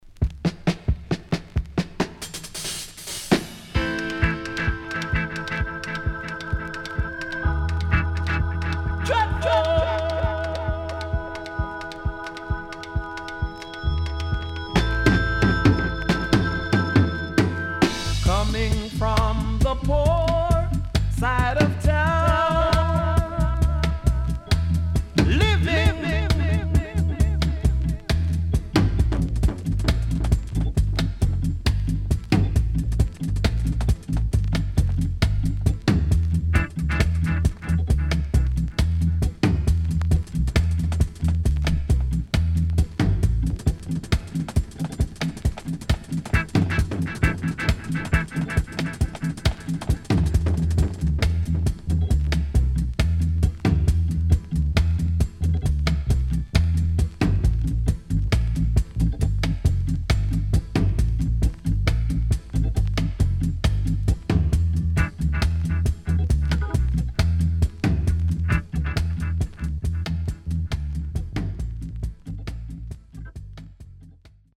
SIDE A:少しチリノイズ入ります。